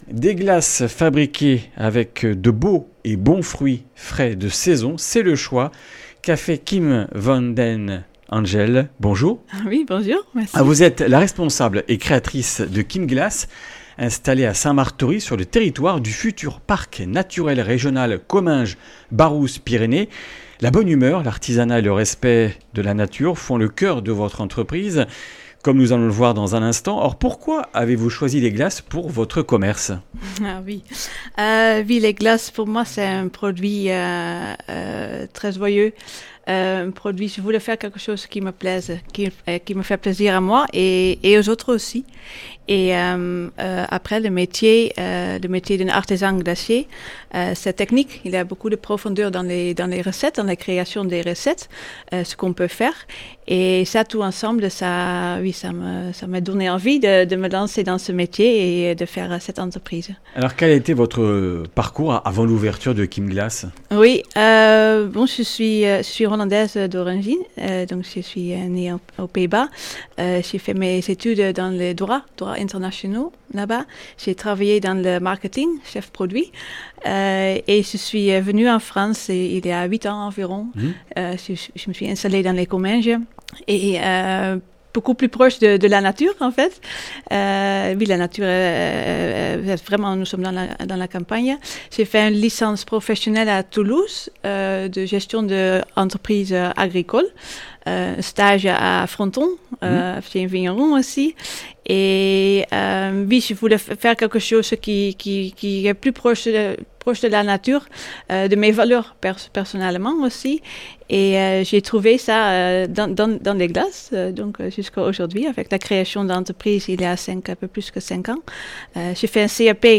Comminges Interviews du 04 juin